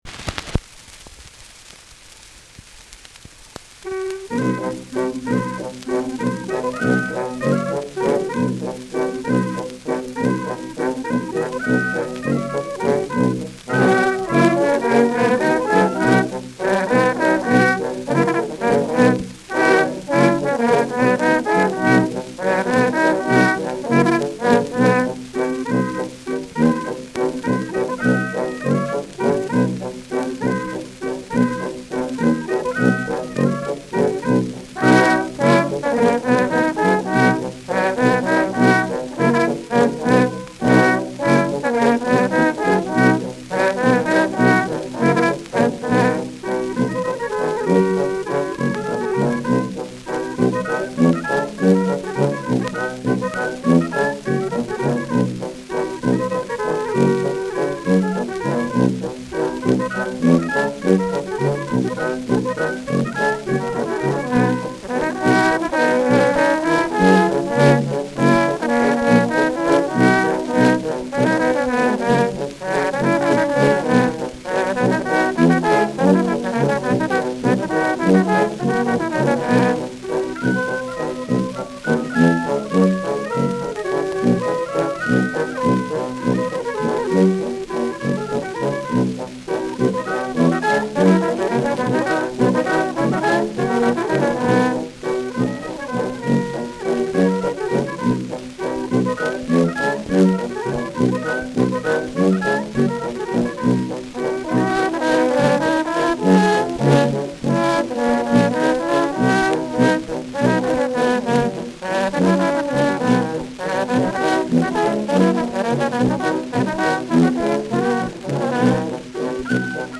Schellackplatte
Tonrille: Kratzer Durchgehend Leicht : Berieb 11 Uhr Leicht
Die taktwechselnden Tanzmelodien werden dort nach wie vor bei vielen Musizier- und Tanzgelegenheiten gespielt.